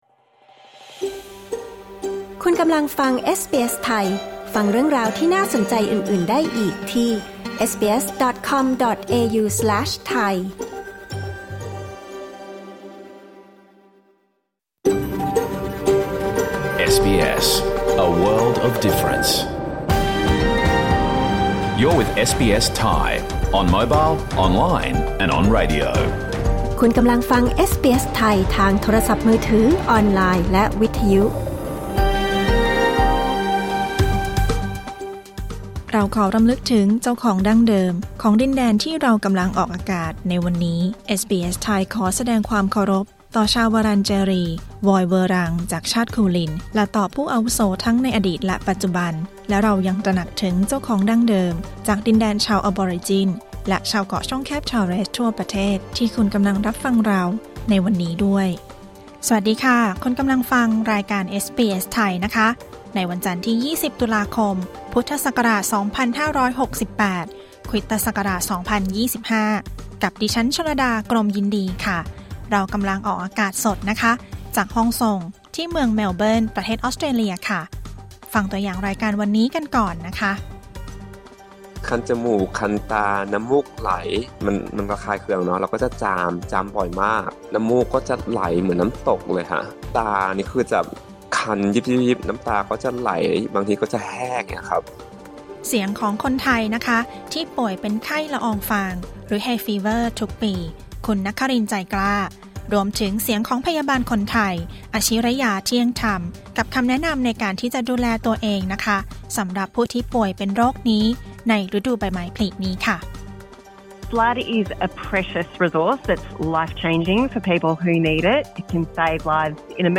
รายการสด 20 ตุลาคม 2568